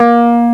WURLITZ.WAV